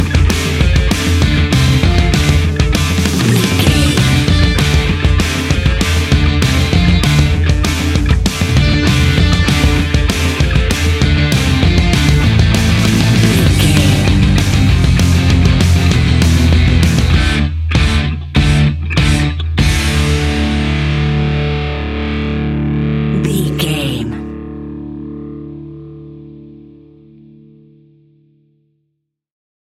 Uplifting
Fast paced
Ionian/Major
Fast
hard rock
distortion
punk metal
instrumentals
Rock Bass
heavy drums
distorted guitars
hammond organ